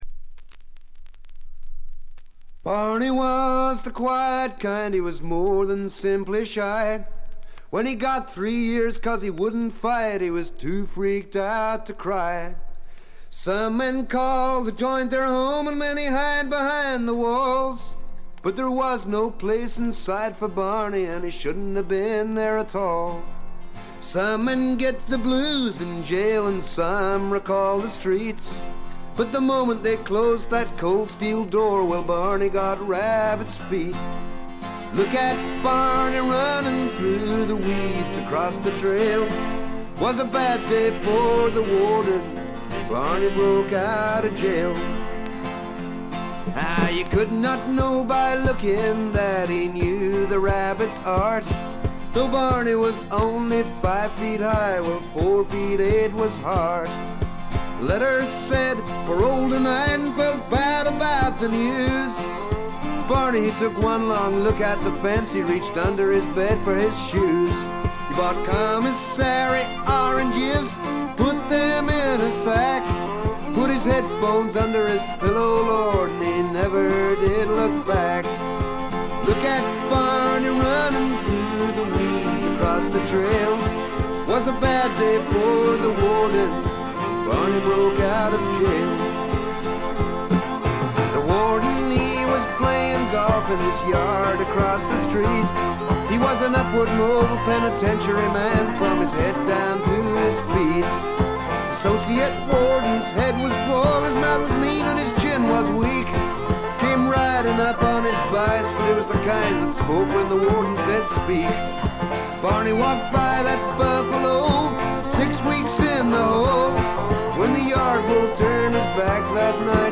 わずかなノイズ感のみ。
録音もベルギーで行われており、バックのミュージシャンも現地組のようです。
試聴曲は現品からの取り込み音源です。
Vocals, Backing Vocals, Guitar, Harmonica